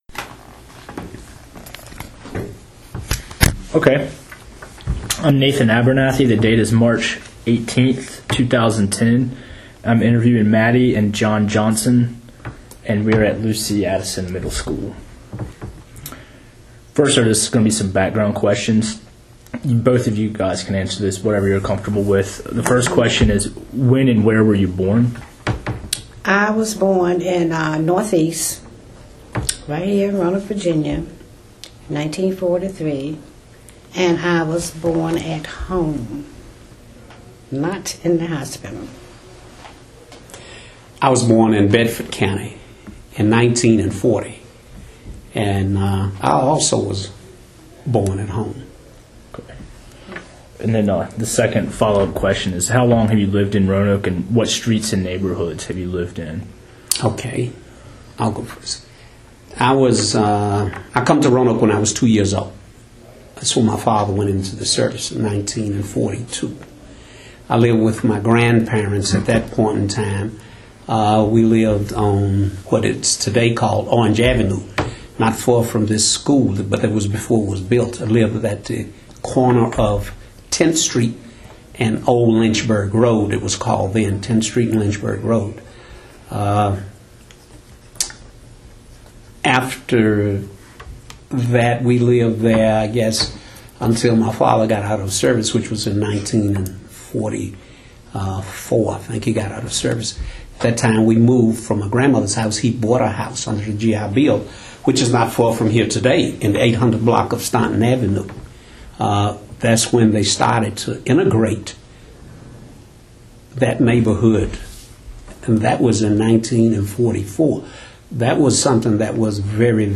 Neighborhood History Interview
Location: Lucy Addison Middle School